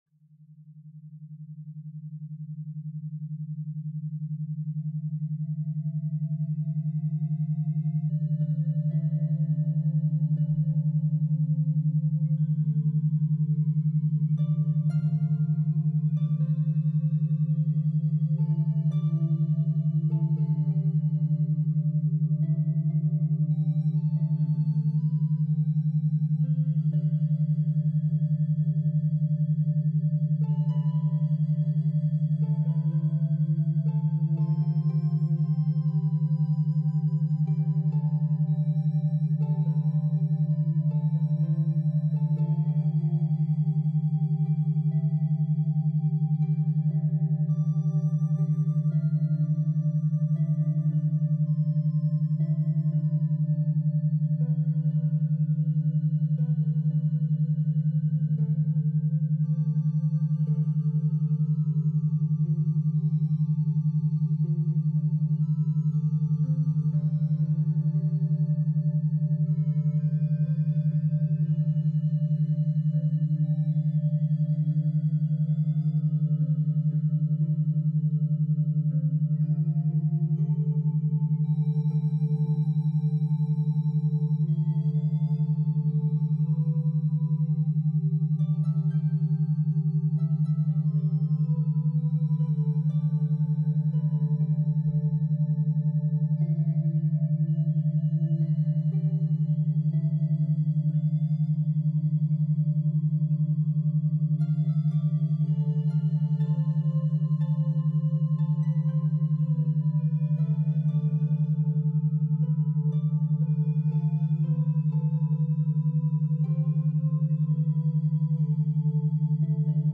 Self Connection Meditation – 432 + 3.4 Hz Binaural State